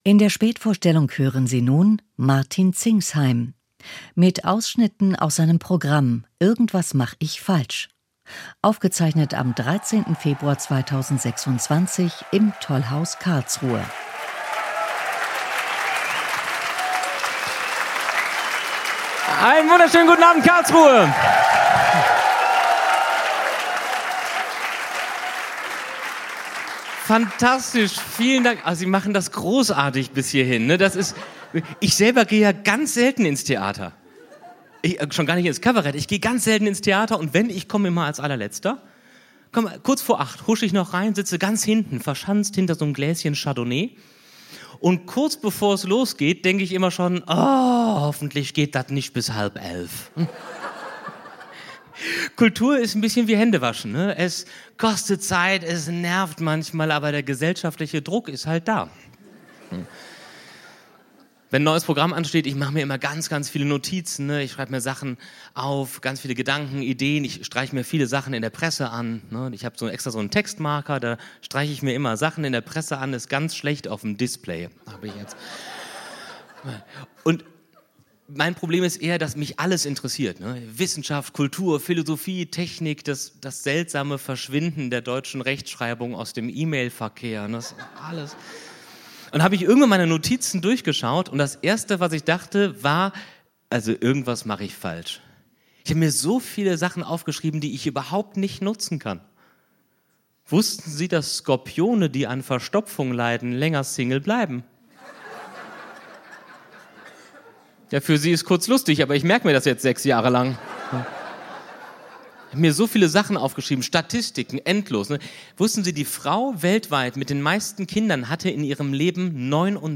Martin Zingsheim – Irgendwas mach ich falsch | Kabarett
Mitschnitt aus dem Tollhaus Karlsruhe am 13. Februar 2026.